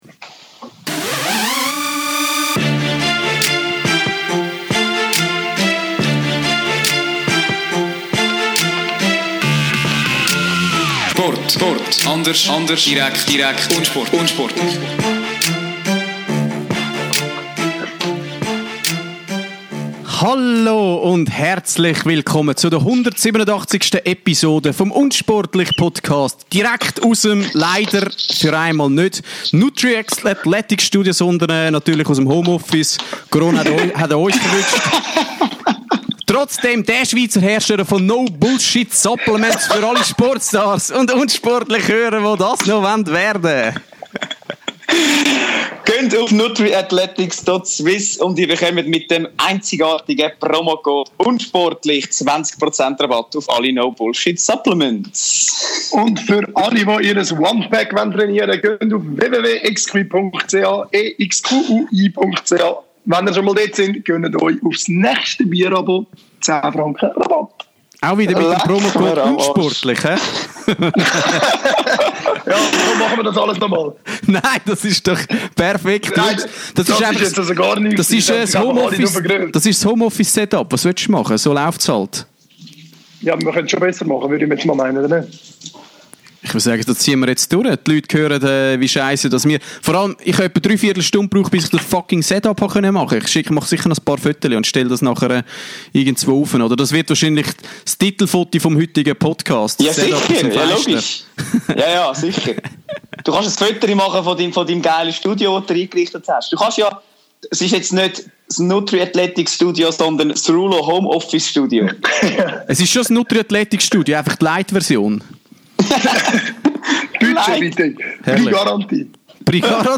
Darum haben wir unser Setup kurzer Hand ins Home Office verlegt.
Ab sofort podcasten wir Remote! Natürlich dreht sich wieder fast alles um die lahmgelegte Sportwelt.